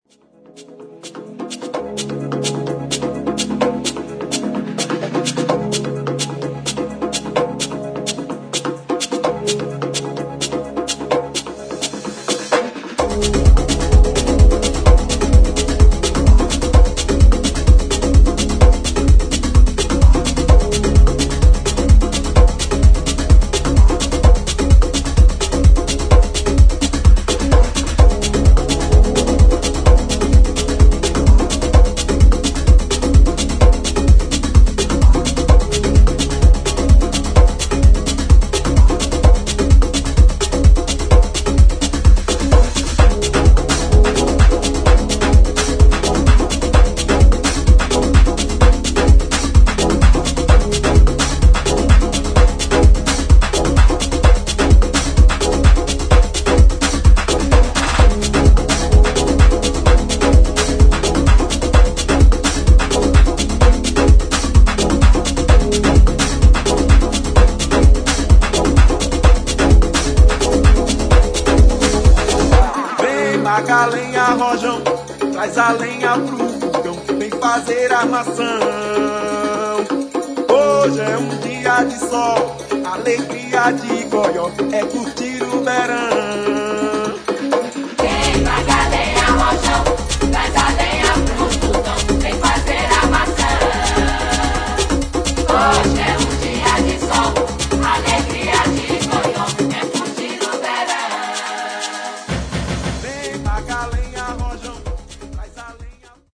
[ HOUSE | EDIT ]